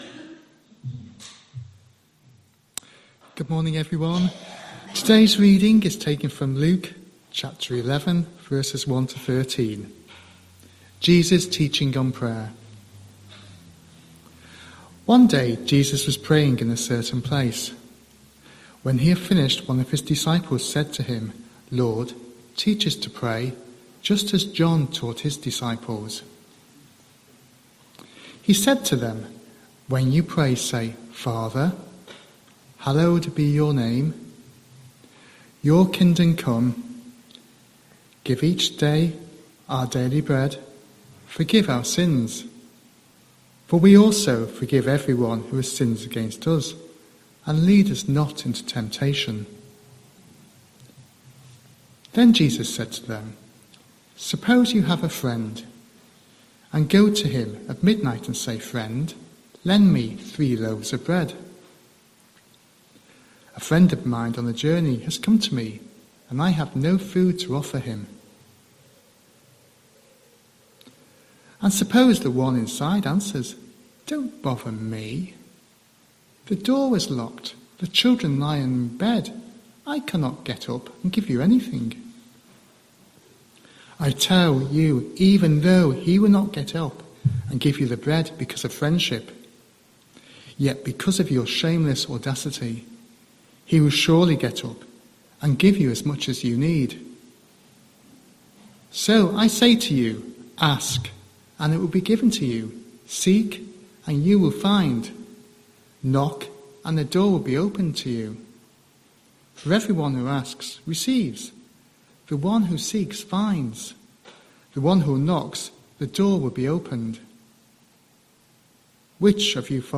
27th July 2025 Sunday Reading and Talk - St Luke's